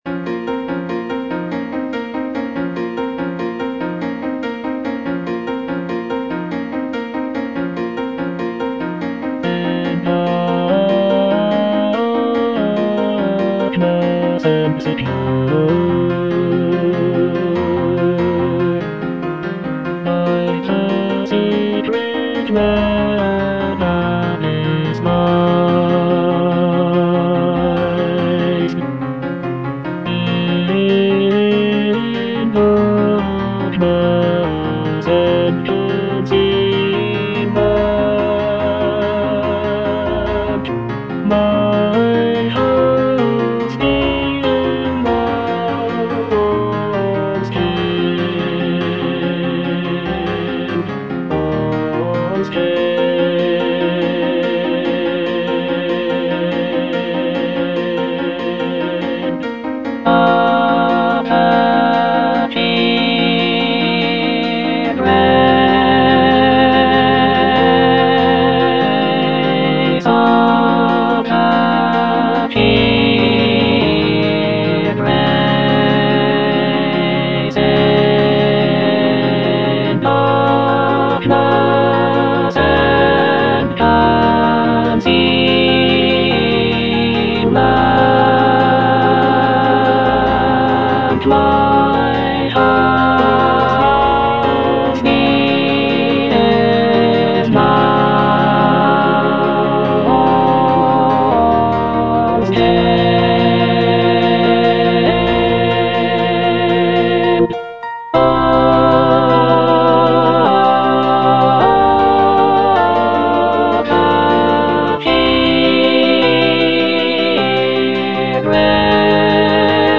alto II) (Emphasised voice and other voices
choral work